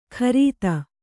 ♪ krīta